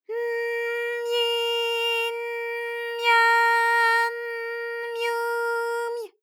ALYS-DB-001-JPN - First Japanese UTAU vocal library of ALYS.
my_n_myi_n_mya_n_myu_my.wav